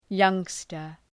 Προφορά
{‘jʌŋstər}